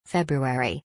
• February دومین سال میلادی و تلفظ آن /febjəˌweriˈ/ است.